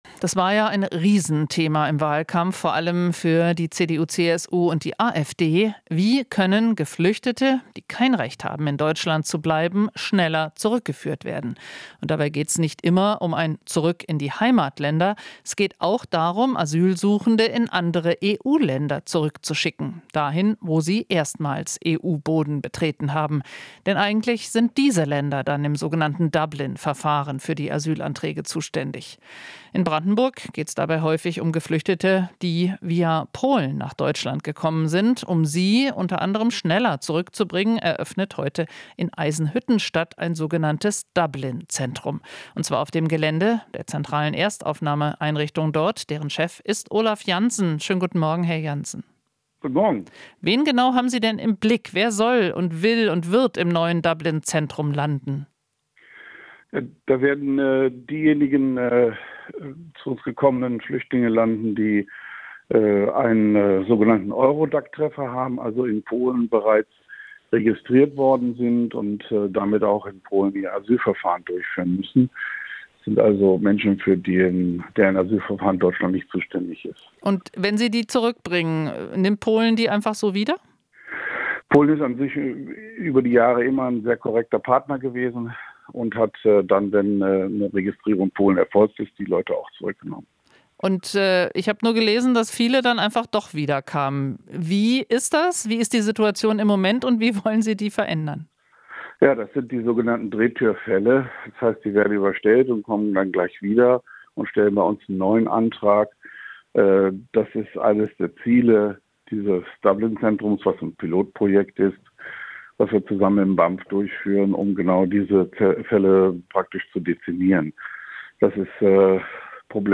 Interview - "Dublin-Zentrum" für Ausreisepflichtige eröffnet in Eisenhüttenstadt